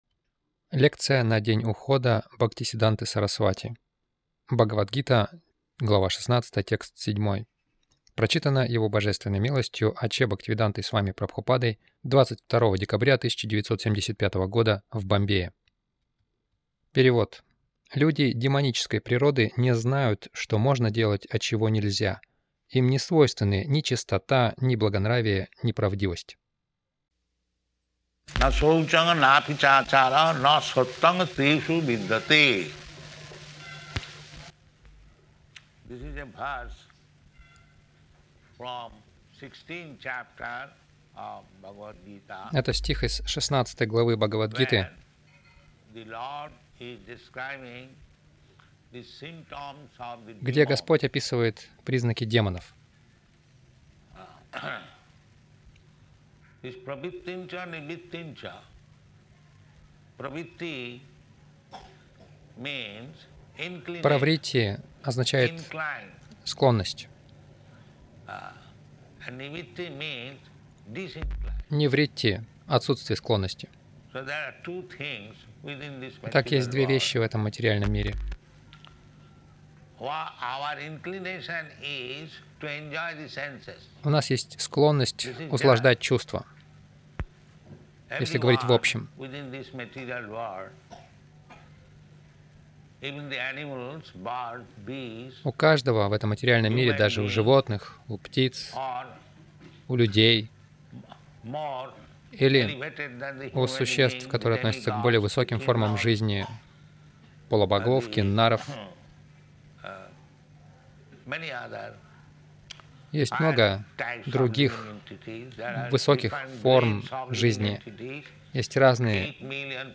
Милость Прабхупады Аудиолекции и книги 22.12.1975 Праздники | Бомбей День ухода Бхактисиддханты Сарасвати, БГ 16.07 — Что принимать и чего избегать Загрузка...